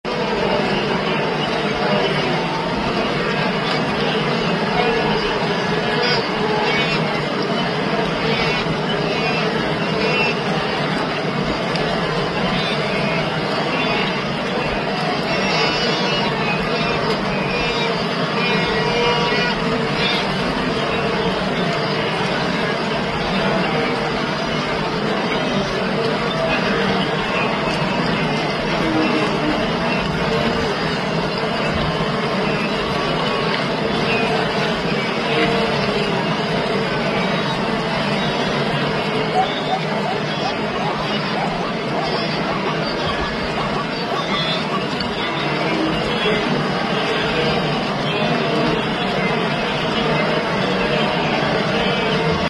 Wildebeest migration involves hippos as